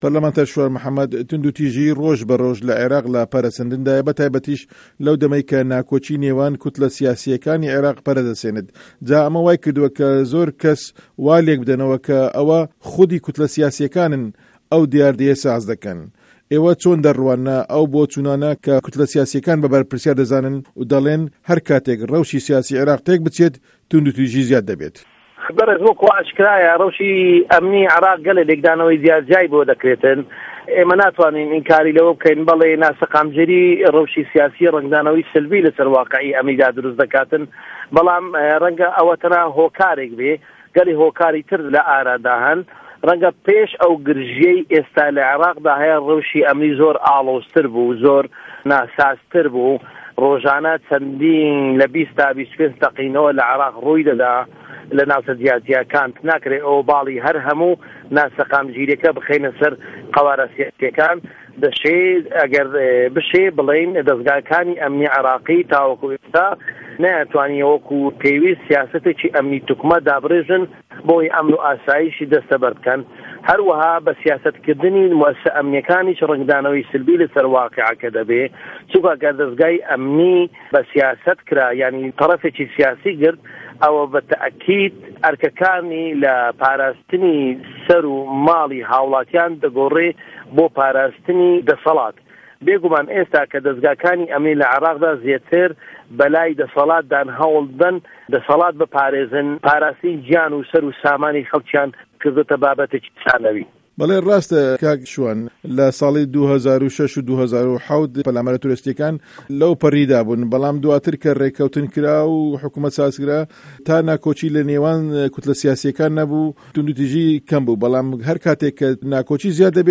وتووێژی شوان محه‌مه‌د